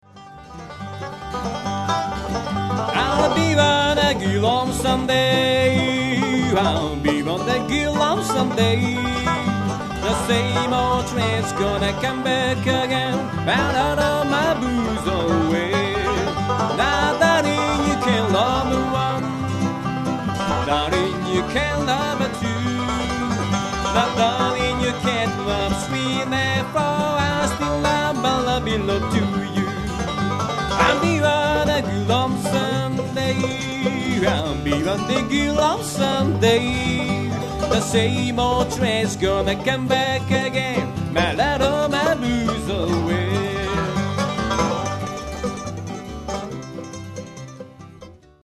ブルーグラスの響き
しかも、その音色のきれいなこと。
マンドリンとウッドベースも達者な演奏で、全く脱帽でした。